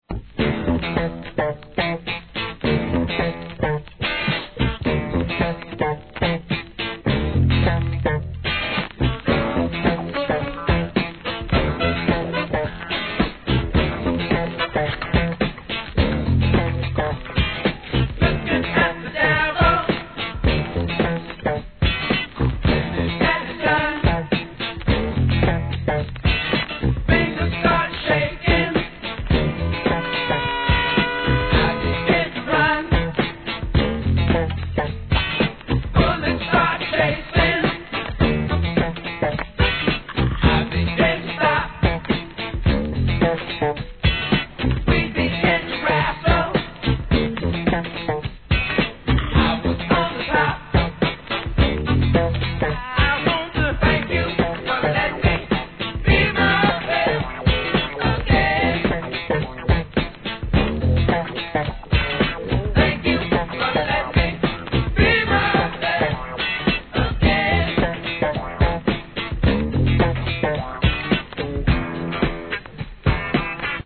¥ 1,650 税込 関連カテゴリ SOUL/FUNK/etc...